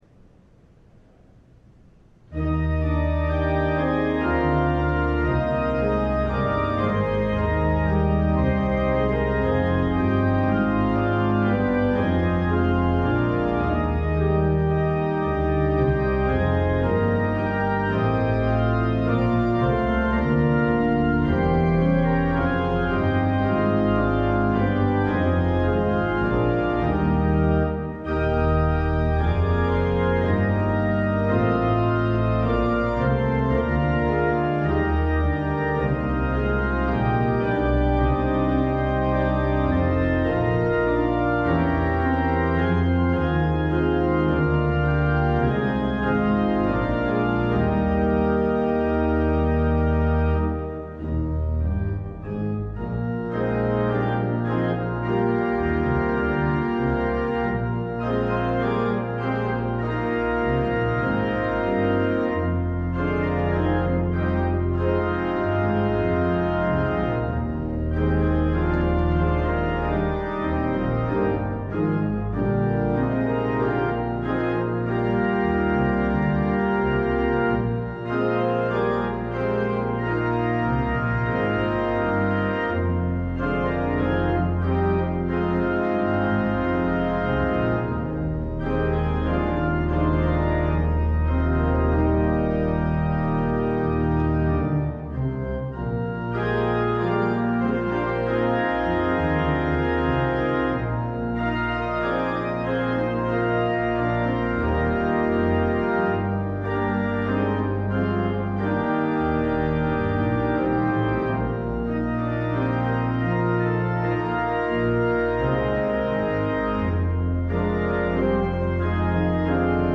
LIVE Evening Worship Service - Misery and Belonging